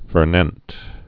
(fər-nĕnt) or for·ninst (-nĭnst) also fer·ninst (-nĭnst)